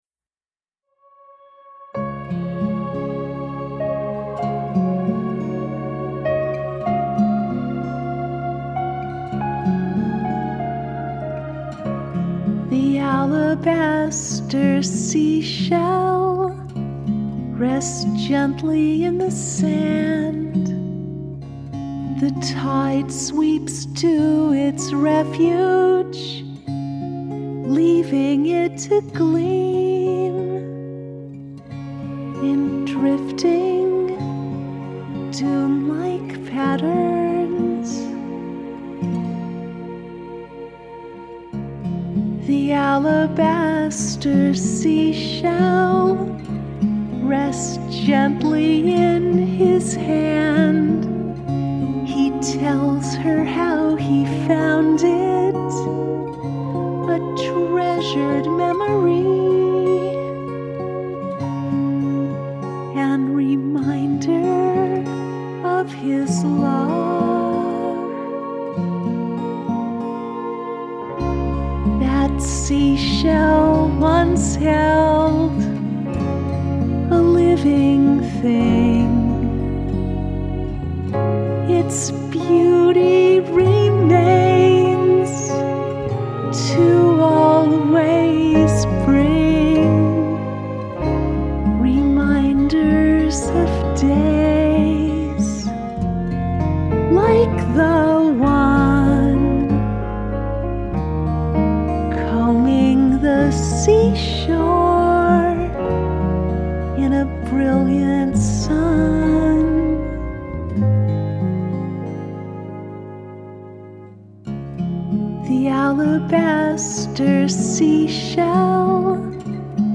I share a recent acoustic recording of this song: